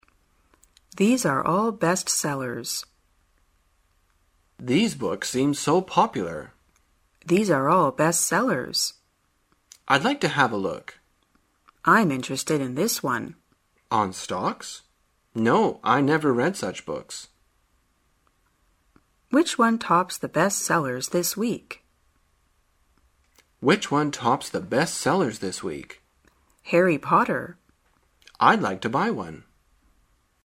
在线英语听力室生活口语天天说 第36期:怎样说畅销的听力文件下载,《生活口语天天说》栏目将日常生活中最常用到的口语句型进行收集和重点讲解。真人发音配字幕帮助英语爱好者们练习听力并进行口语跟读。